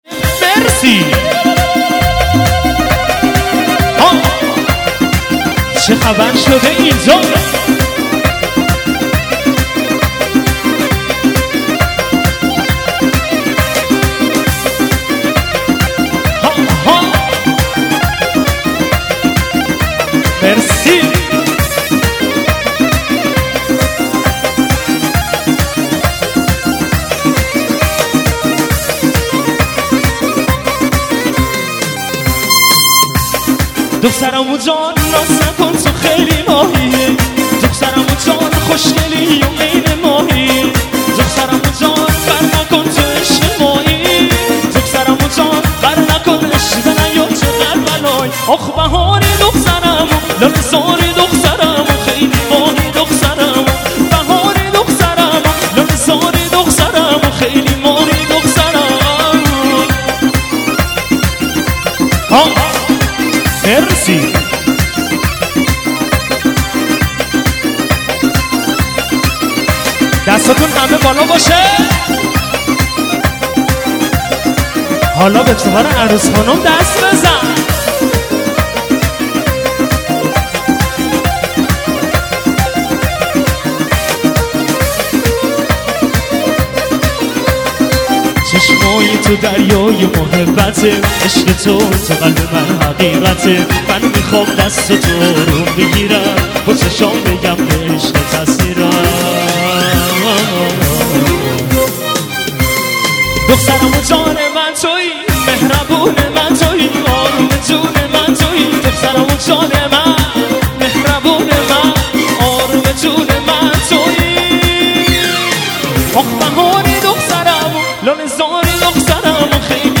آهنگ شاد ارکستی